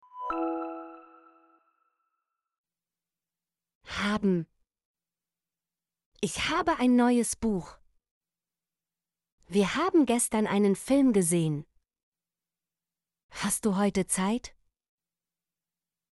haben - Example Sentences & Pronunciation, German Frequency List